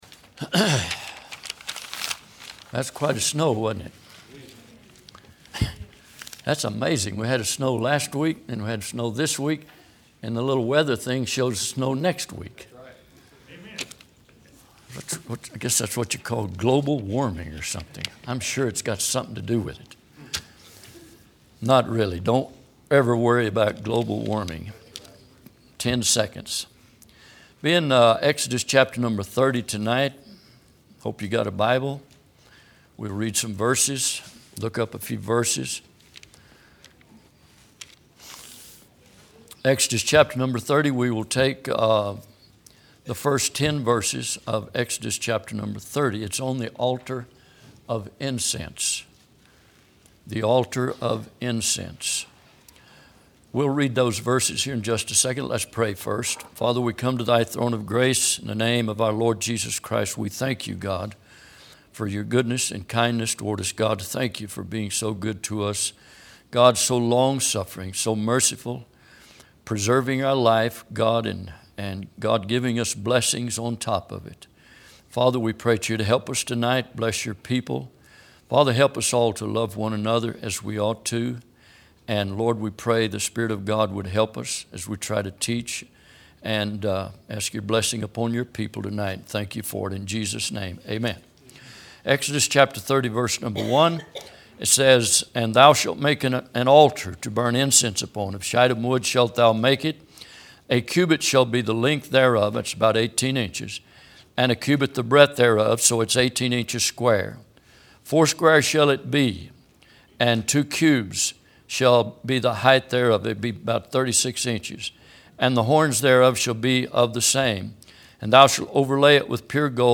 Exodus 30:1-10 Service Type: Midweek Bible Text